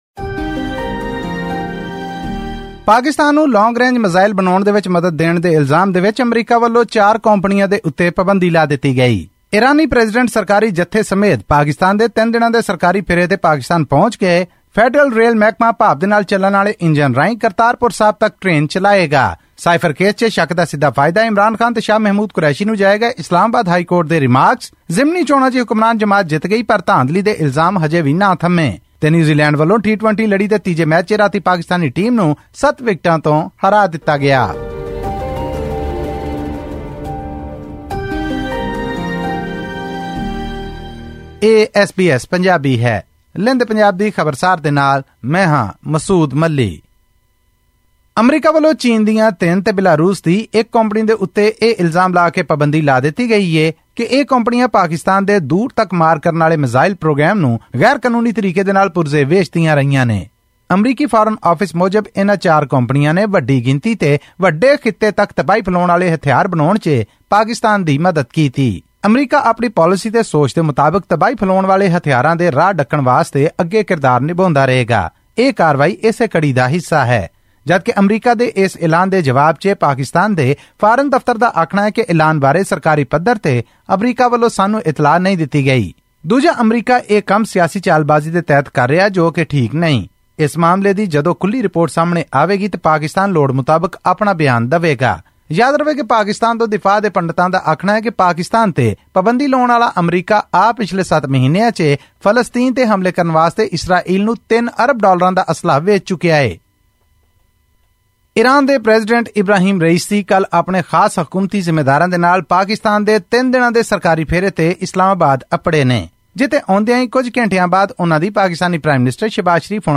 ਰਿਪੋਰਟ